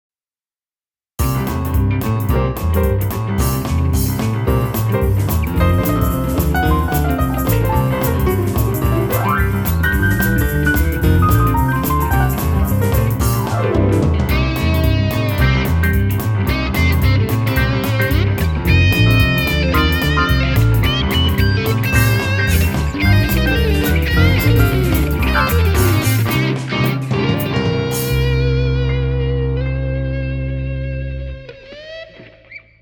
Piano Written/Performed by
Bass/Drums